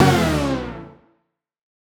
Index of /musicradar/future-rave-samples/Poly Chord Hits/Ramp Down